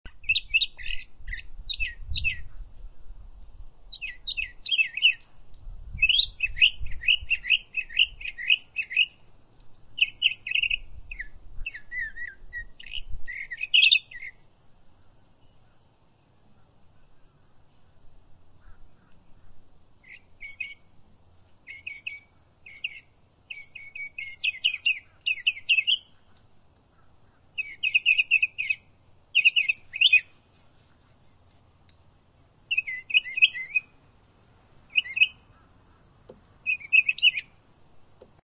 Florida Mockingbird In My Backyard Bouton sonore